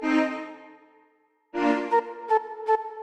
基本的病态嘻哈旋律 158 BPM
Tag: 158 bpm Hip Hop Loops Violin Loops 523.51 KB wav Key : Unknown